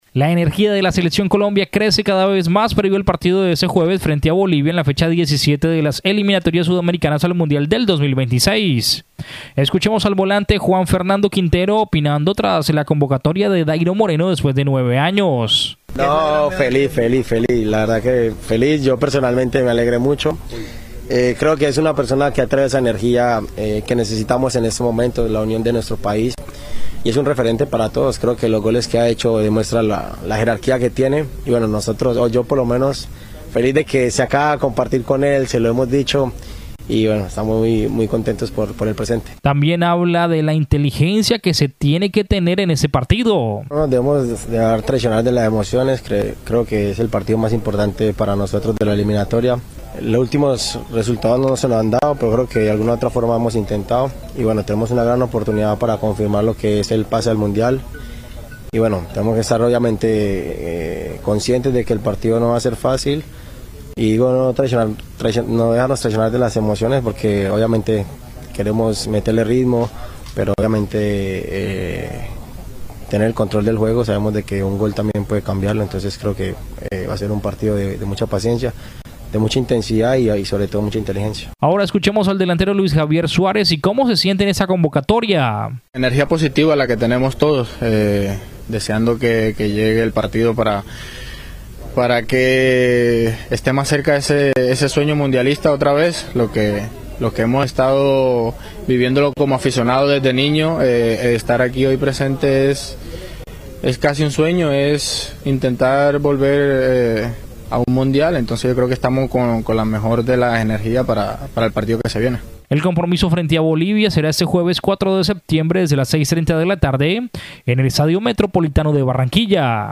[AUDIO] Jugadores de la Selección Colombia hablan tras su llegada a Barranquilla para las Eliminatorias
El volante Juan Fernando Quintero opina tras la convocatoria de Dayro Moreno después de 9 años.